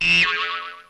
Boing Sound Button: Unblocked Meme Soundboard
Boing Sound Effects